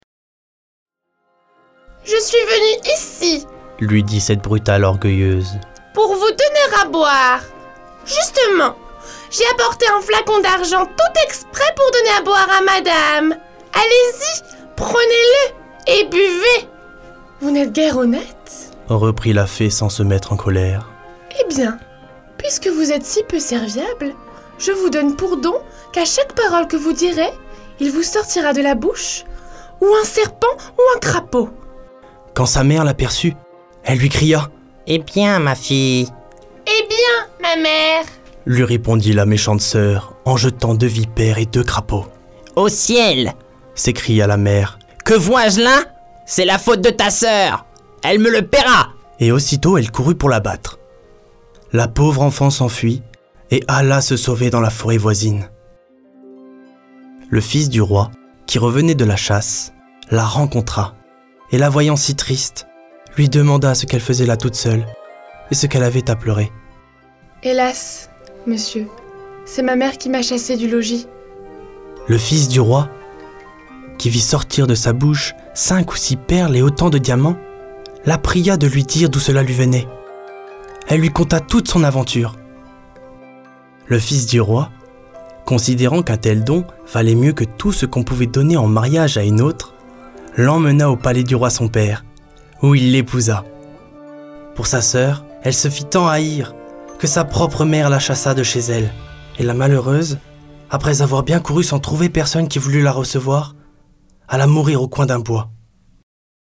Conte pour enfant
17 - 30 ans - Baryton